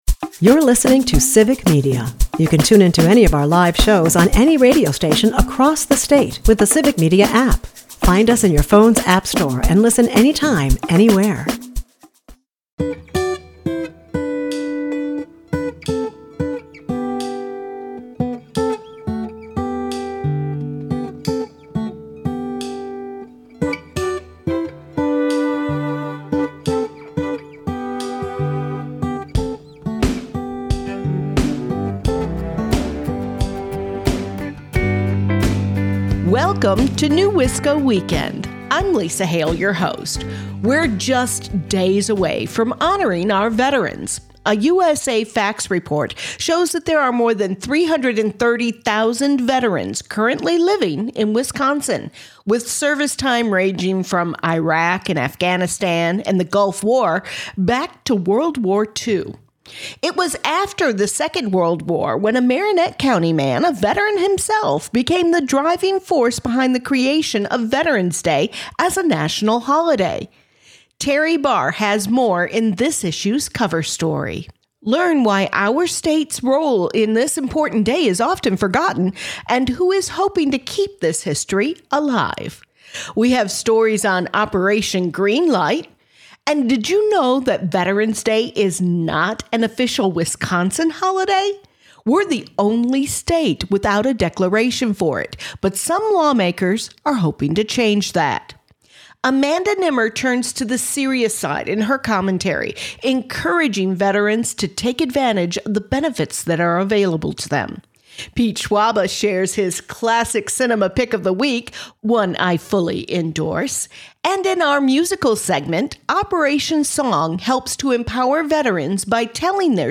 NEWisco Weekend is a part of the Civic Media radio network and airs Saturdays at 8 am and Sundays at 11 am 98.3 and 96.5 WISS.